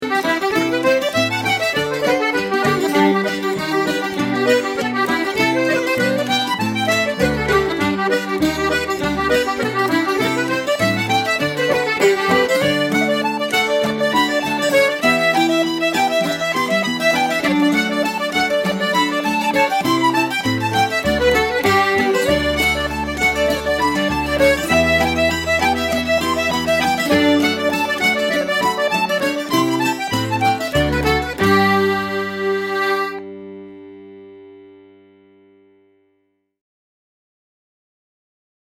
fiddle and accordion.
Now the same reel which has travelled from Ireland to Baltimore via the Catskill Mountains:
I hope you can hear those ‘odd’ notes!